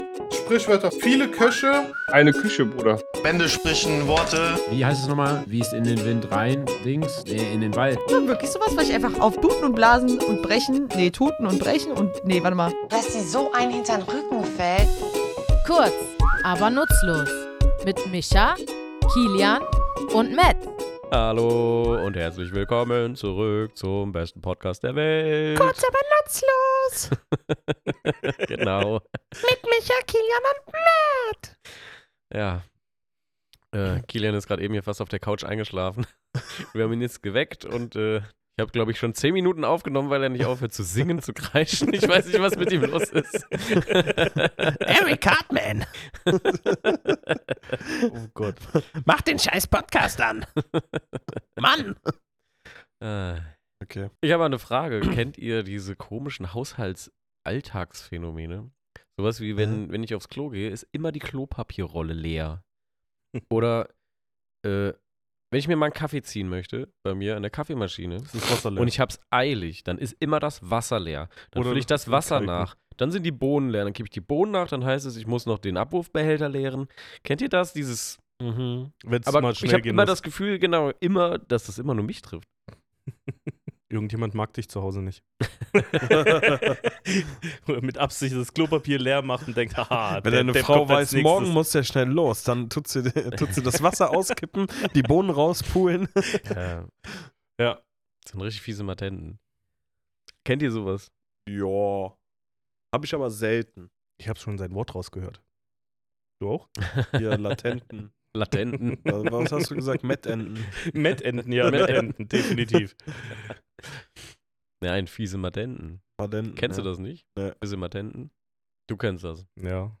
Was hat es mit diesem Ausdruck für Ausreden, Faxen oder unnötige Umstände auf sich, und woher stammt er eigentlich? Wir, drei tätowierende Sprachliebhaber, gehen in unserem Tattoostudio der Herkunft und den möglichen Ursprüngen dieses charmanten Begriffs auf den Grund.